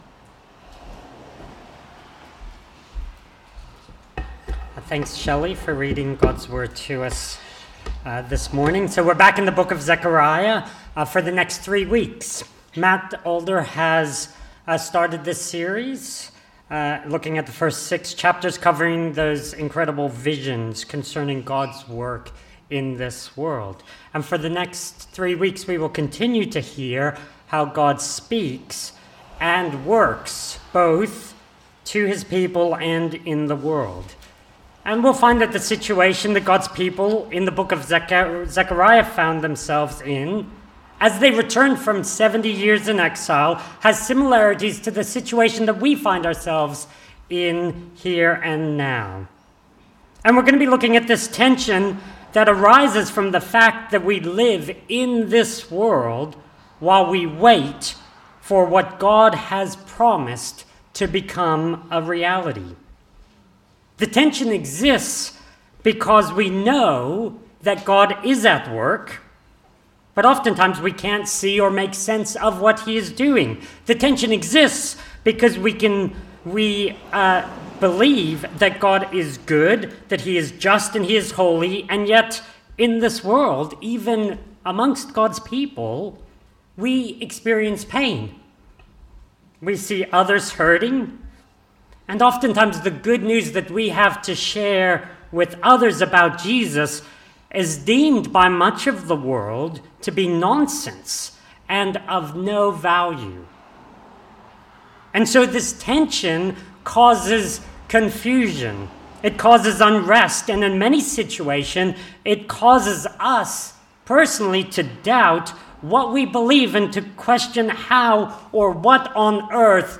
… continue reading 297 قسمت # Christianity # Religion # Anglican # Jesus # Helensburgh # Stanwell # Park # Helensburgh Stanwell Park Anglican A Church # Stanwell Park Anglican A Church # Sermons